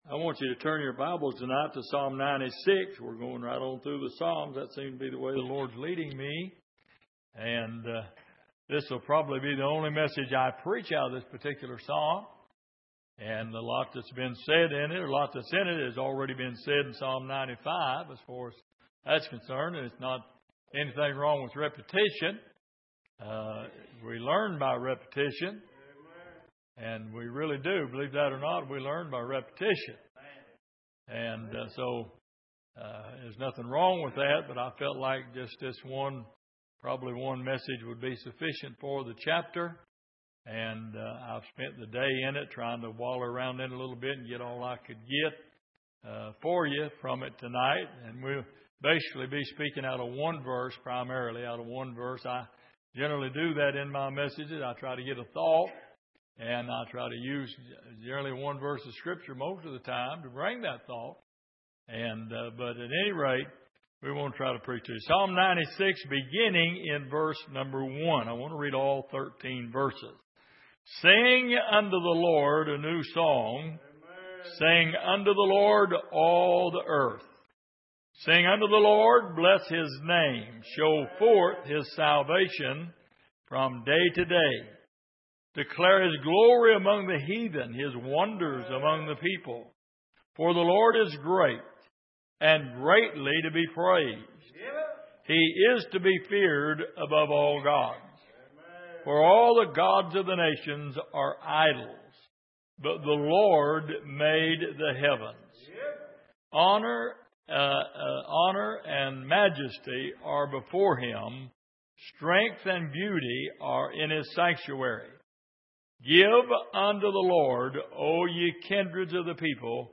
Passage: Psalm 96:1-13 Service: Midweek